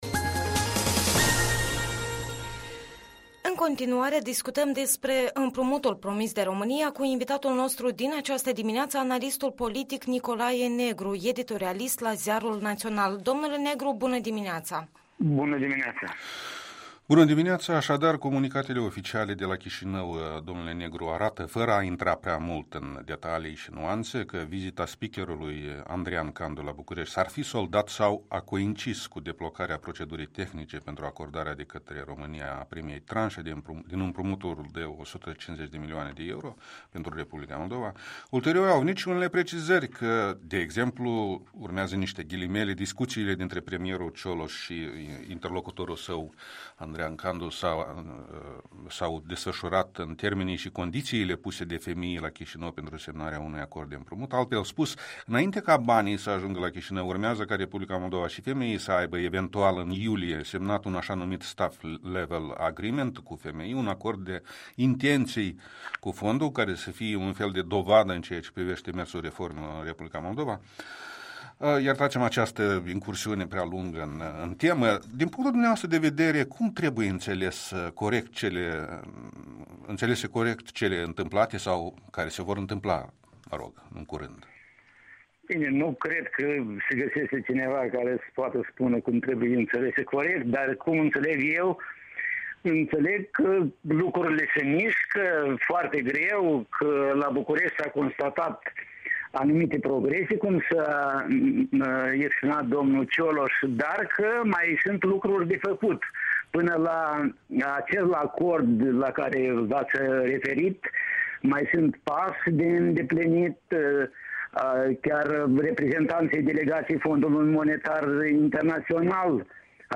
Interviul matinal la EL